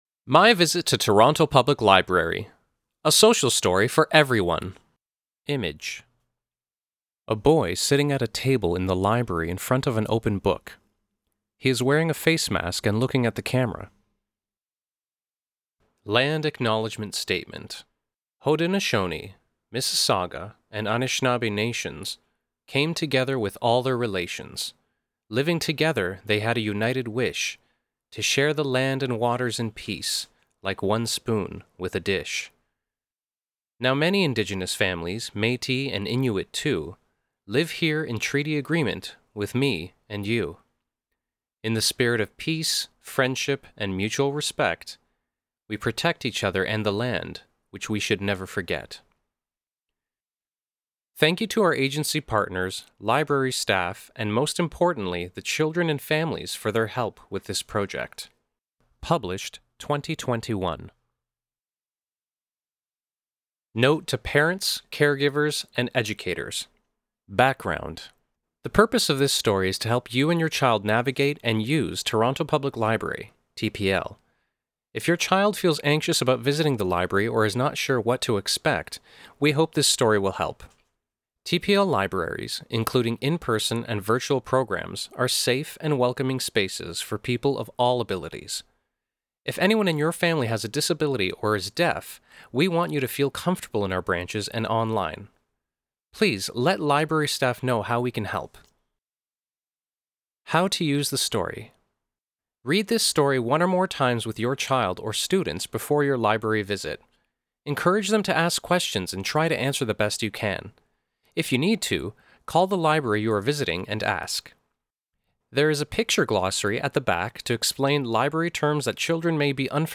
social-story-audio-description.mp3